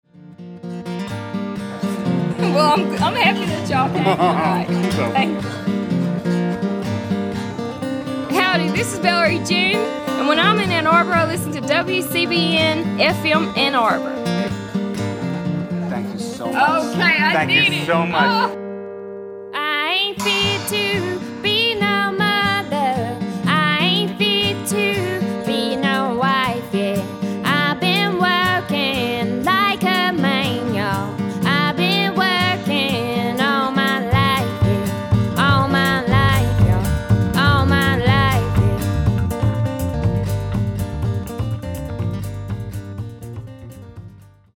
valerie-june-legal-id-mixdown-3.mp3